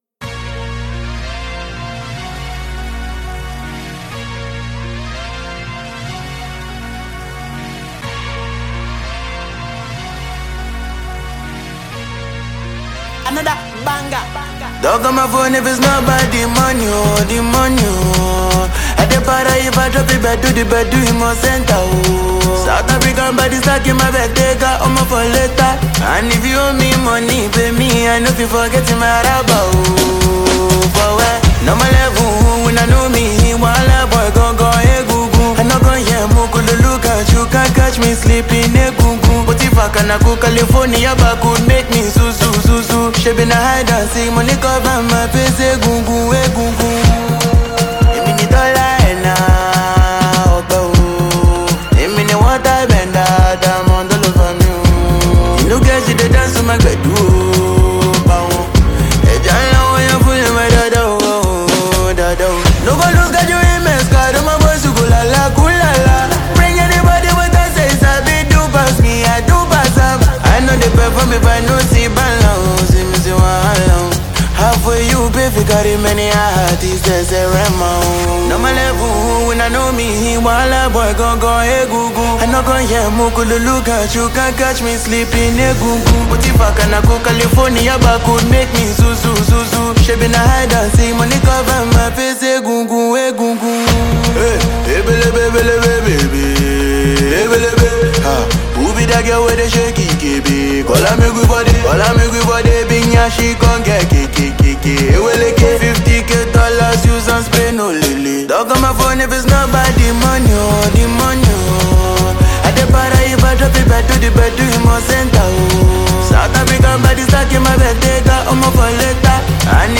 a noteworthy Nigerian afrobeat singer and songwriter.